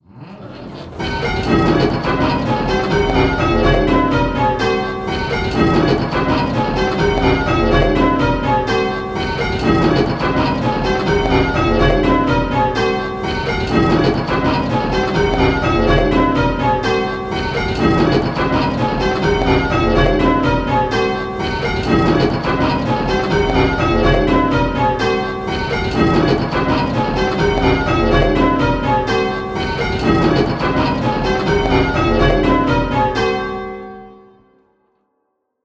Striking 8 Bell Rounds - Pebworth Bells
Striking 8 Bell Rounds - Round 1